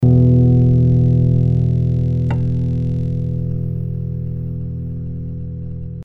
I’ve just installed the new Spectralayers 12 and Unmix Song is giving me some weird resonance/noise, the higher the quality setting the more resonant.
A sharp band pass filter got me to find it in the whole unmixed track at around 5,4kHz.